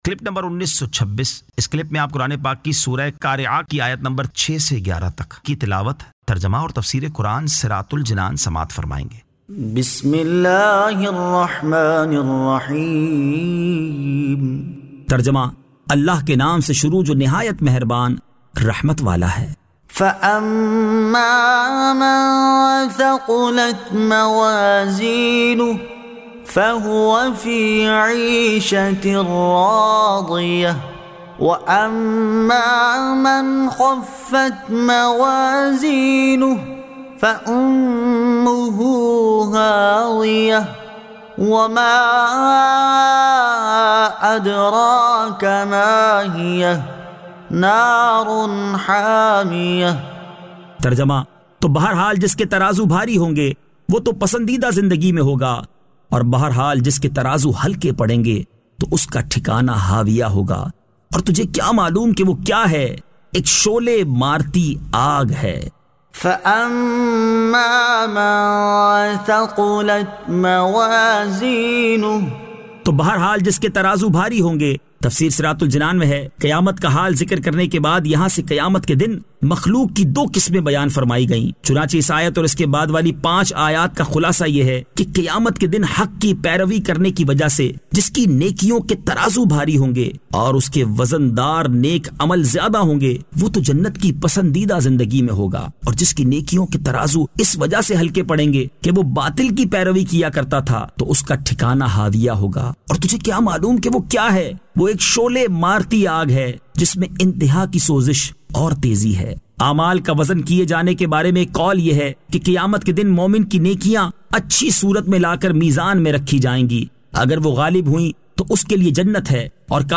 Surah Al-Qari'ah 06 To 11 Tilawat , Tarjama , Tafseer